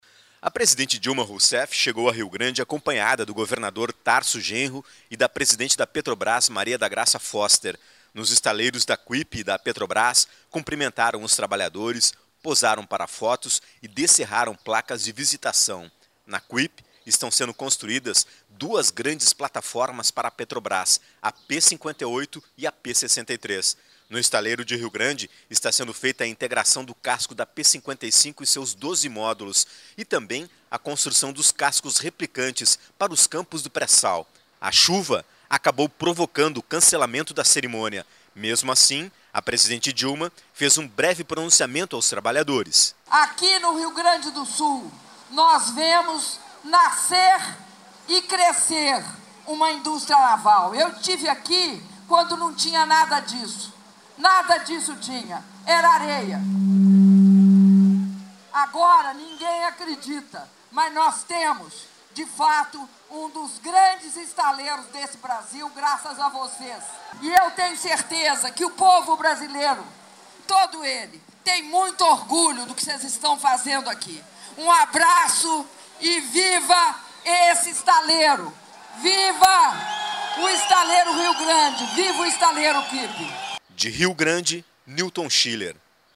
boletim-dilma-rio-grande.mp3